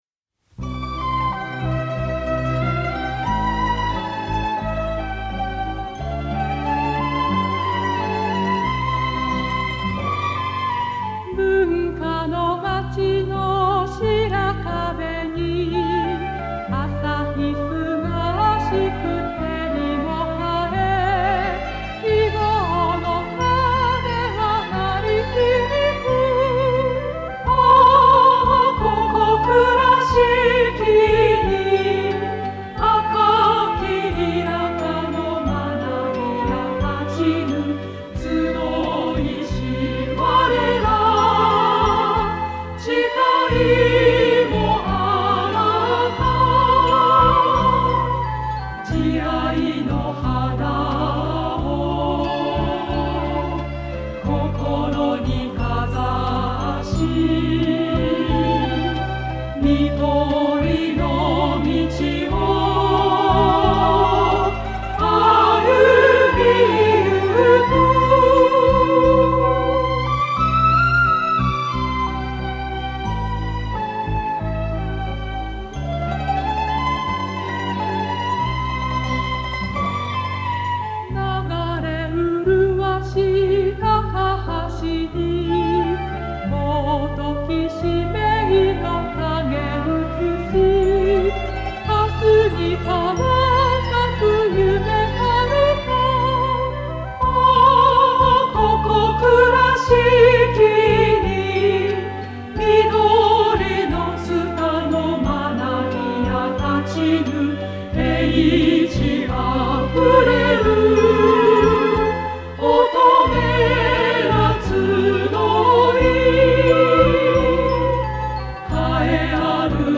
school_song.mp3